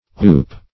Oop \Oop\ ([=oo]p), v. t. [Etymol. uncertain.]